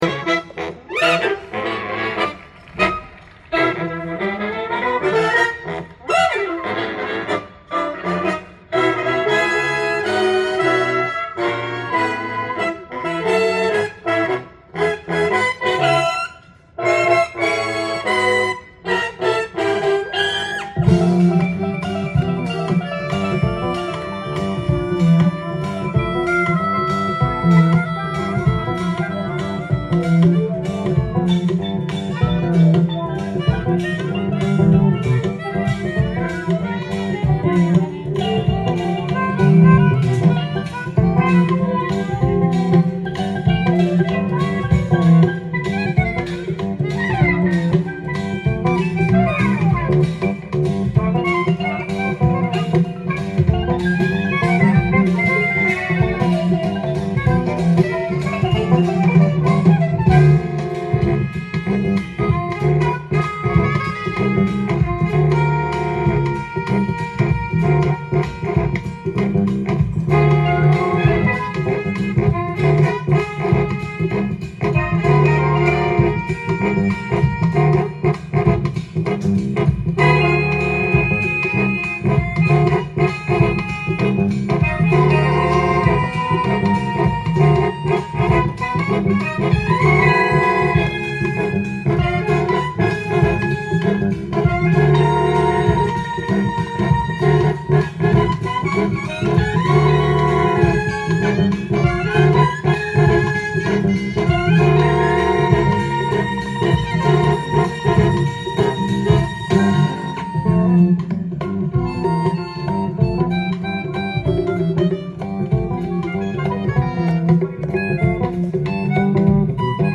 ジャンル：FUSION
店頭で録音した音源の為、多少の外部音や音質の悪さはございますが、サンプルとしてご視聴ください。